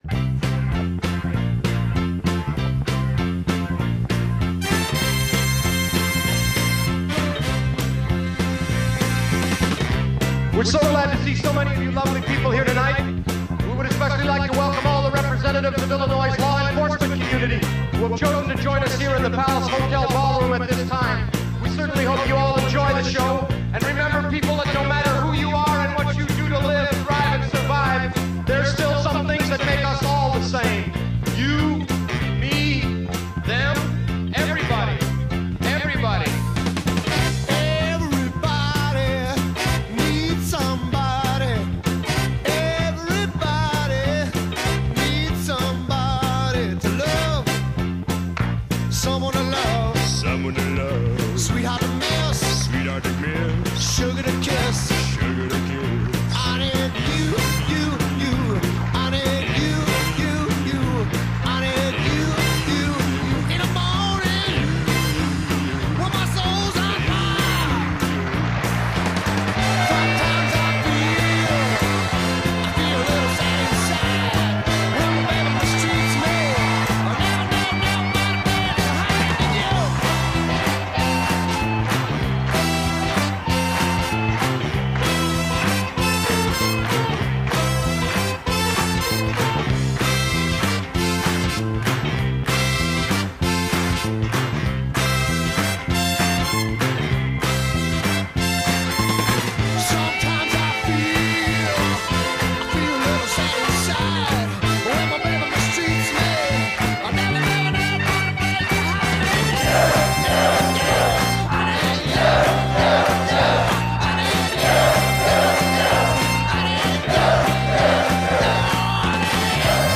Y es que, para celebrar nuestros 500 programas te ofrecemos una serie con el mejor Blues concentrado y hoy disfrutaremos con el que se hace hoy en día en bittorrent, UPVRadio y esta misma web.